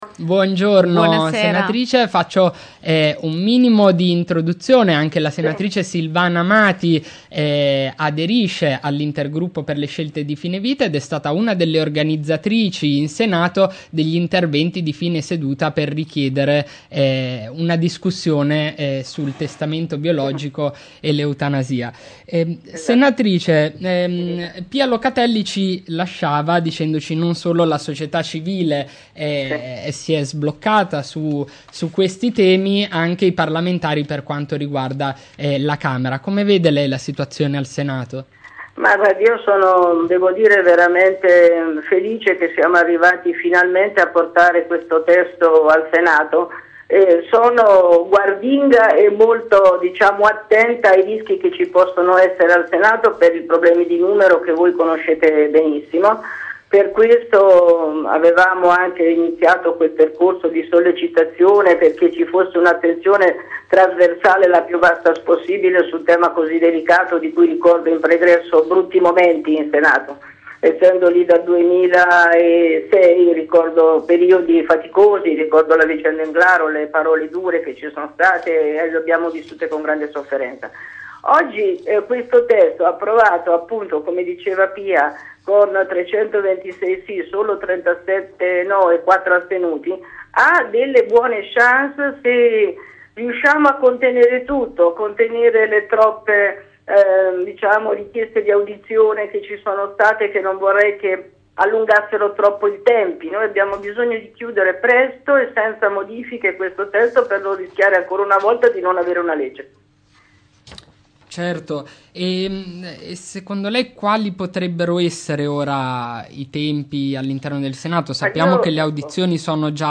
Martedi' 16 maggio presso la Sala Aldo Moro di Palazzo Montecitorio, la presidente della Camera, Laura Boldrini, ha aperto i lavori del convegno "Specie diverse, stesse tutele - Proposte di equiparazione dei reati online".
Fotografie - Registazione integrale dell'evento